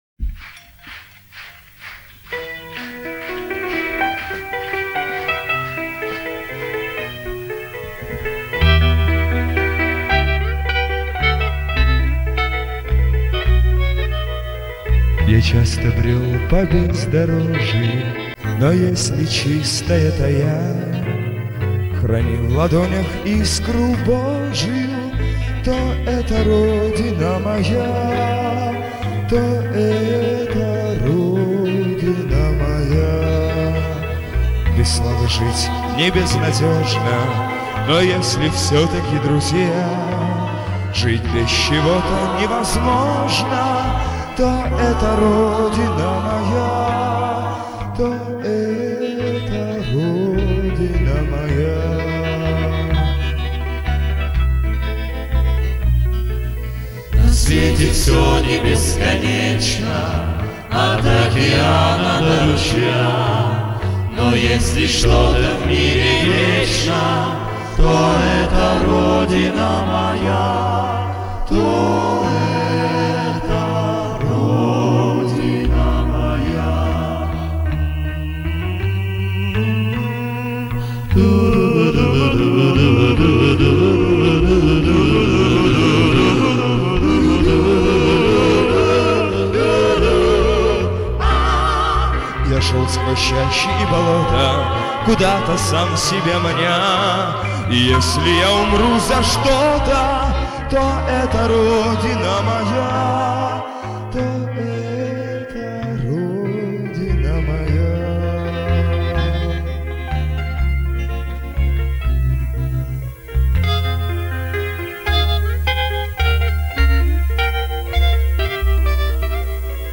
с концерта 1975 года